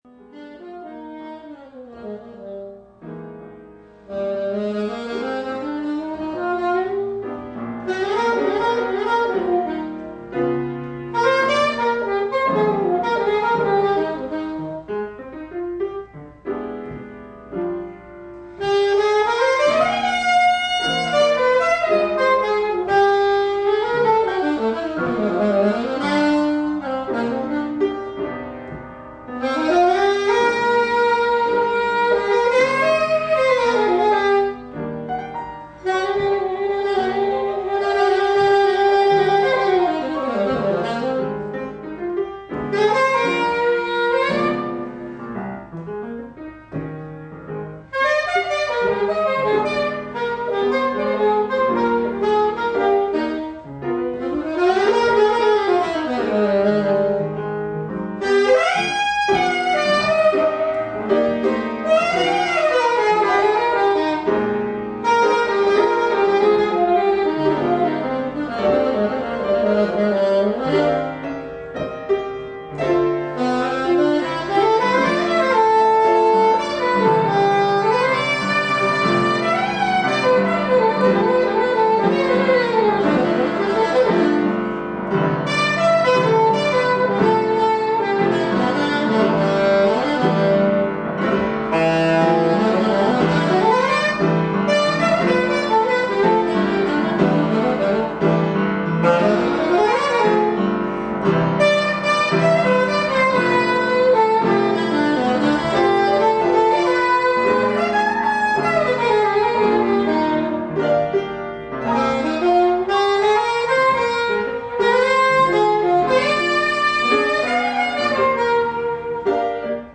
ｓａｘ ＆ ｐｉａｎｏ ｄｕｏ 2